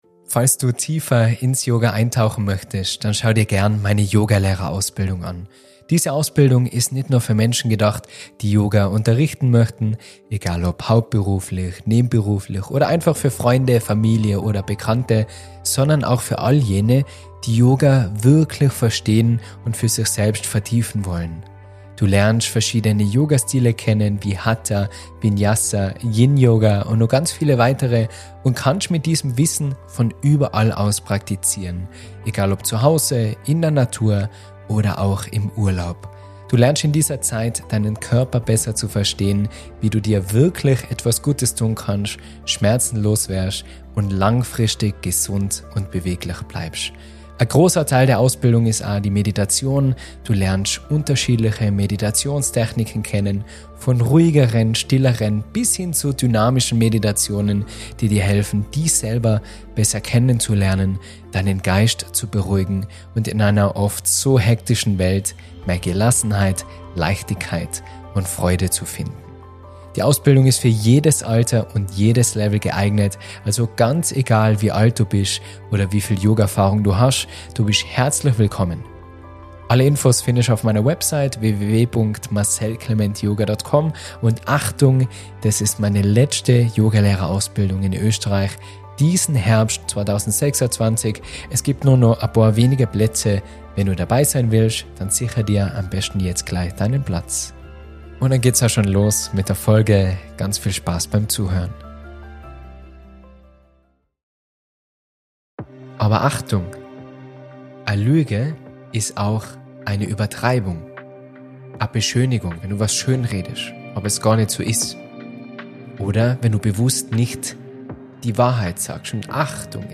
In diesem Vortrag spreche ich über Satya, einen zentralen Aspekt der Yoga-Philosophie.